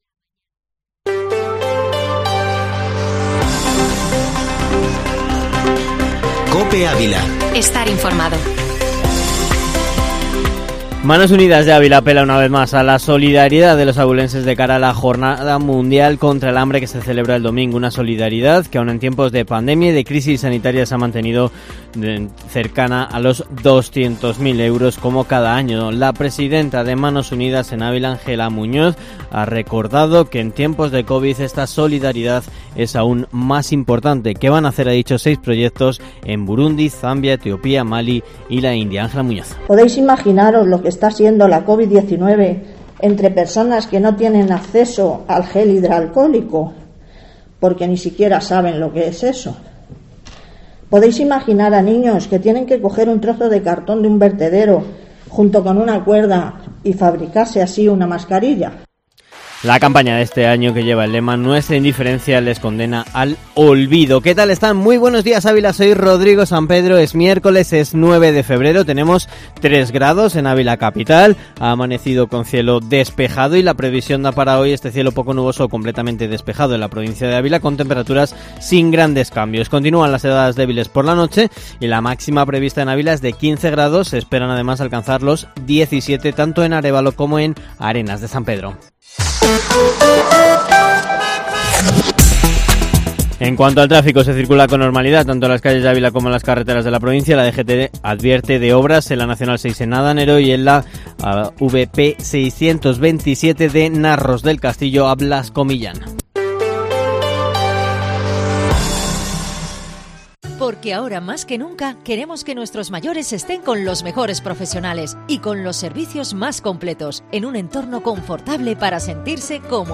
Informativo Matinal Herrera en COPE Ávila -9-febrero